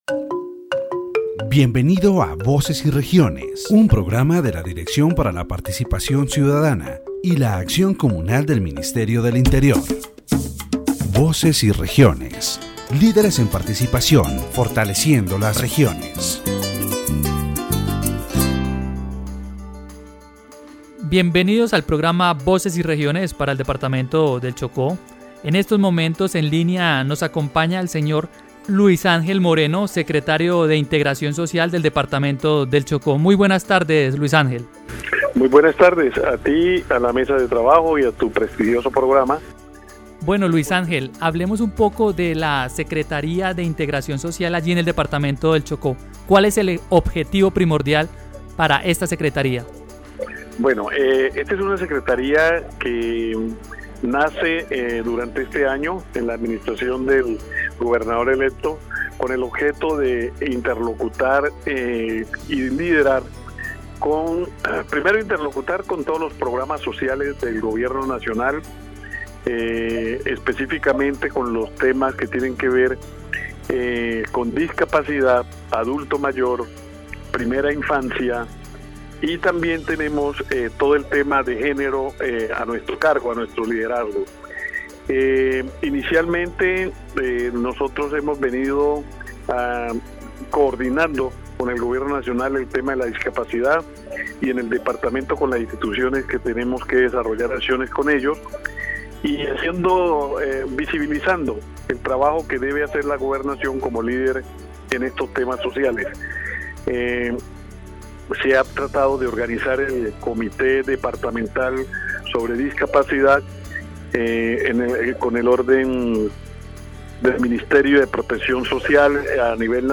The radio program presents a conversation with Luis Ángel Moreno, Secretary of Social Integration of the Department of Chocó, who explains the progress and challenges of his secretariat. They talk about programs to benefit the disabled population, early childhood, and the employment and education situation in the department. It highlights the importance of characterization of the disabled population, the creation of a disability committee, and work towards the labor and educational inclusion of these people.